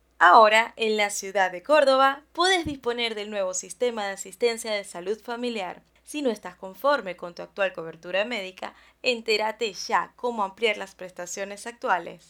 Sprechprobe: Sonstiges (Muttersprache):
I really like the imitations of characters, I have good diction, pronunciation, voice projection and work with a neutral accent in Spanish and English.